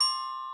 glock_C_5_2.ogg